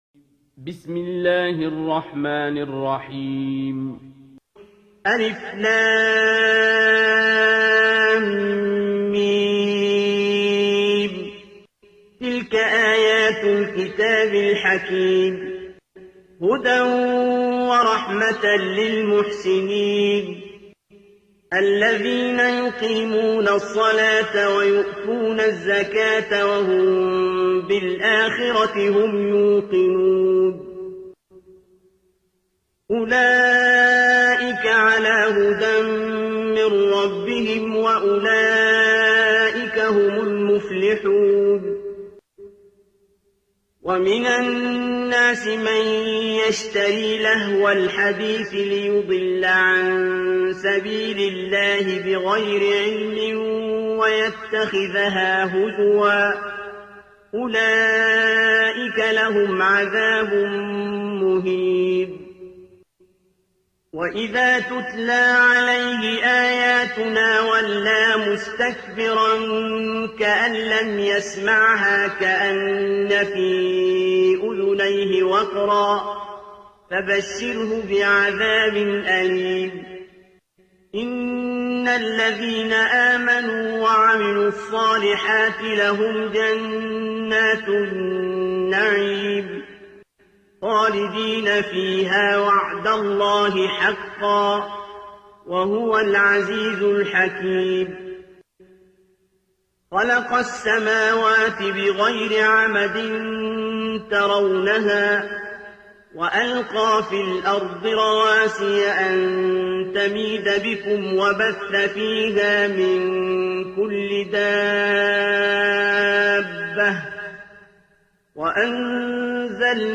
ترتیل سوره لقمان با صدای عبدالباسط عبدالصمد
031-Abdul-Basit-Surah-Luqman.mp3